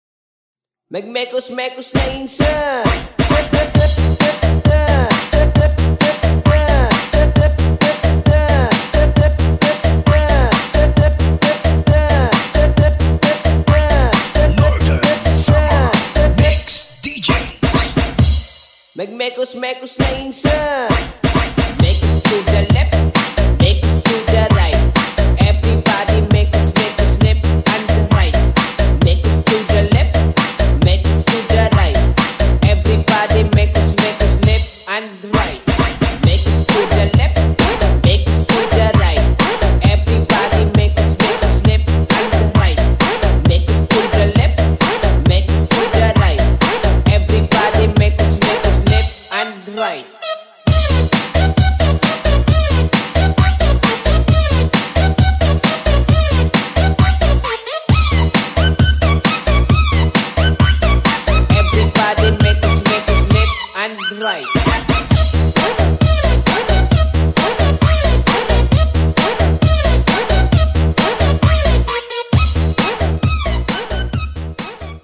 𝙎𝙡𝙤𝙬𝙚𝙙&𝙍𝙚𝙫𝙚𝙧𝙗 😌🎧 75 seconds 2 Downloads SELOS